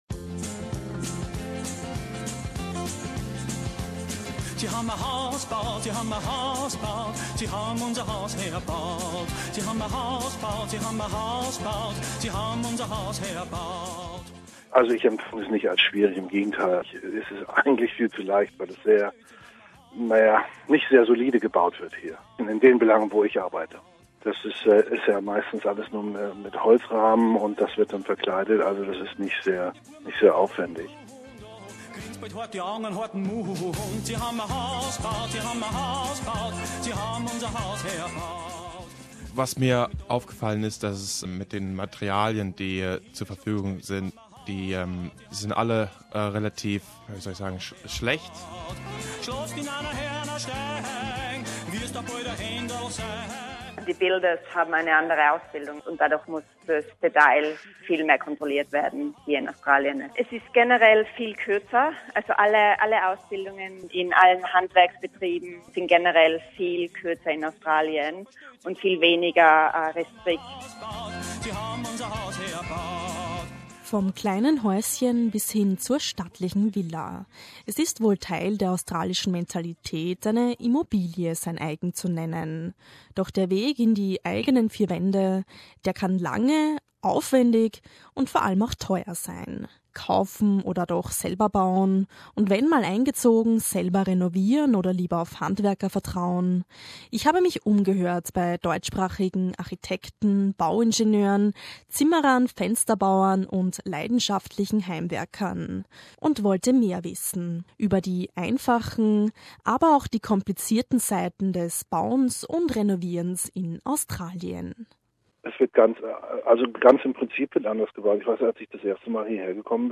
Beim Material vertraut man lieber auf Deutschland, Handwerker und Bauarbeiter sind nicht immer bestens ausgebildet - doch in Sachen solide und energieeffiziente Bauweise holt Australien mittlerweile auf. Über die Licht- und Schattenseiten zum Thema Hausbau geben deutschsprachige Architekten, Unternehmer und Heimwerker Auskunft.